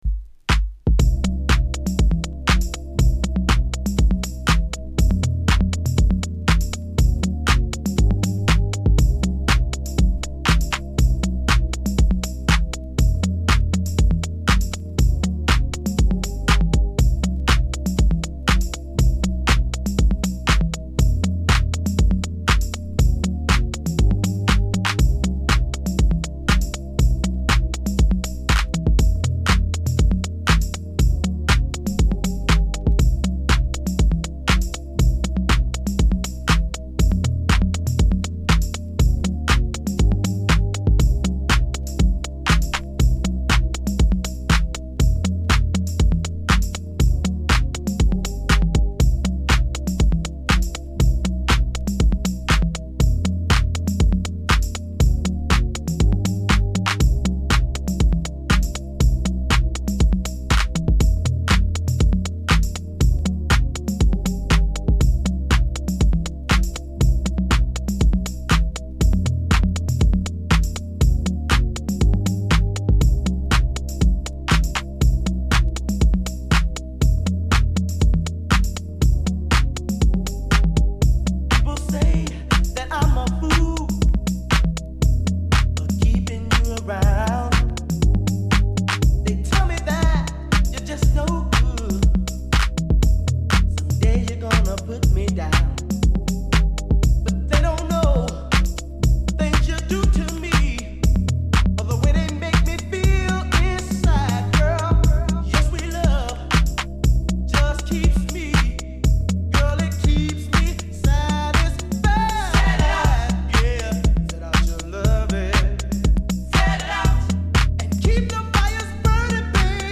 Beats Electronic R&B